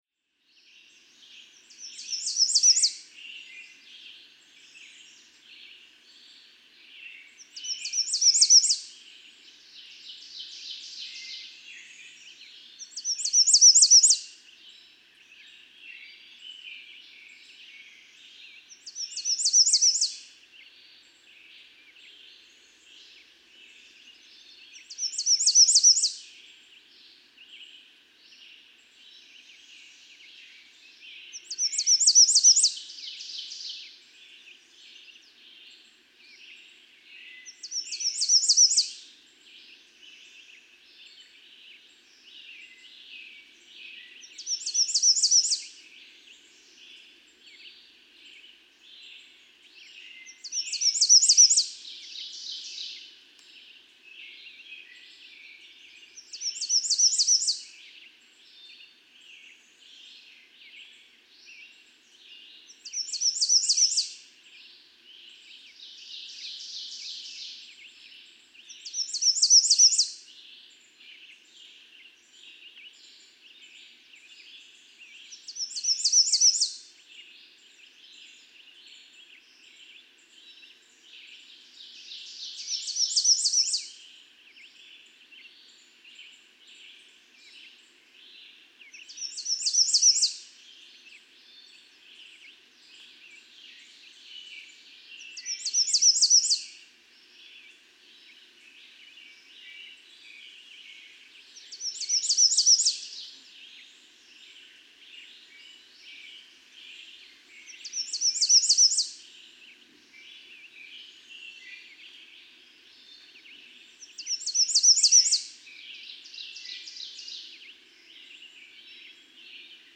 American redstart
Day song of male 1, above.
Cricket Hill, Conway, Massachusetts.
564_American_Redstart.mp3